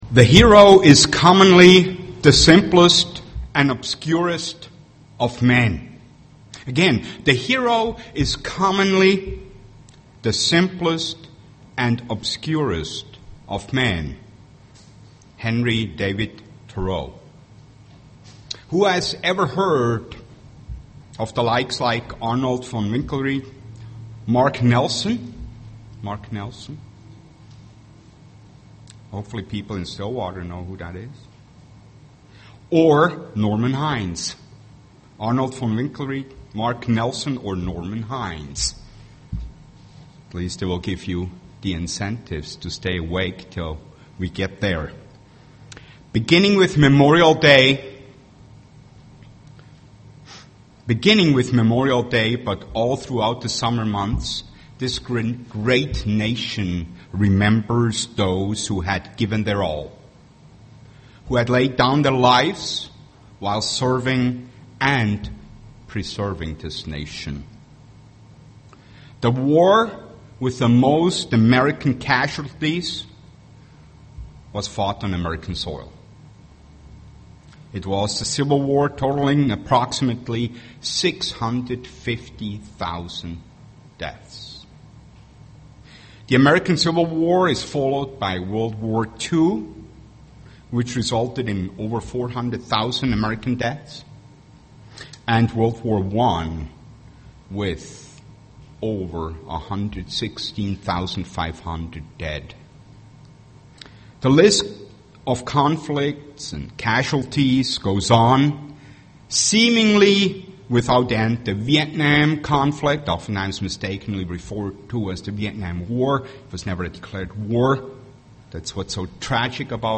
UCG Sermon Faith heros Studying the bible?
Given in Twin Cities, MN